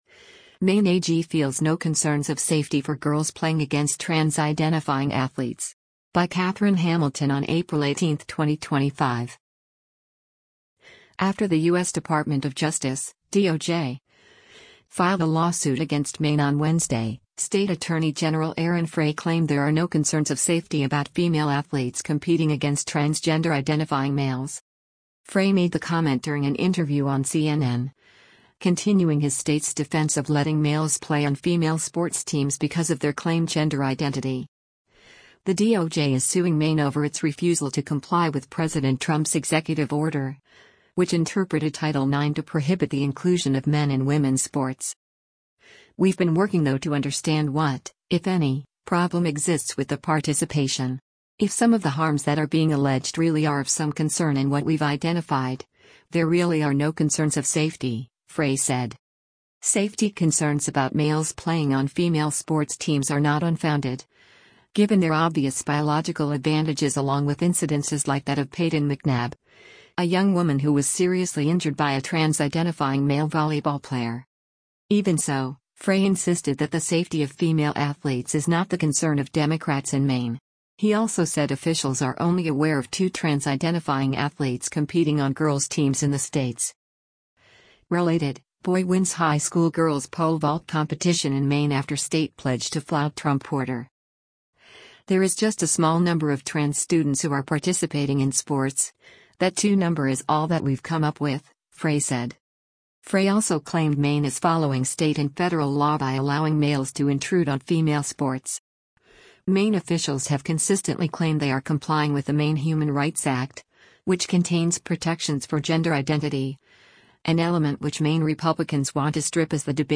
Frey made the comment during an interview on CNN, continuing his state’s defense of letting males play on female sports teams because of their claimed “gender identity.”